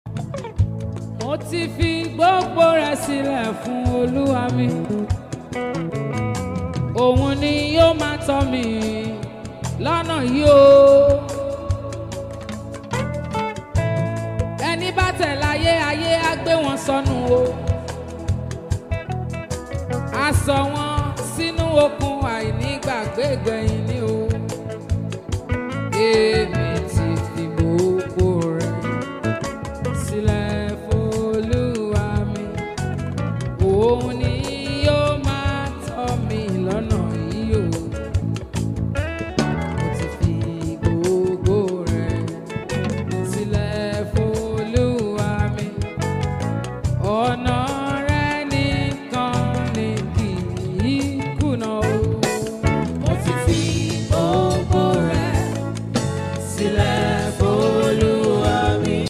live ministration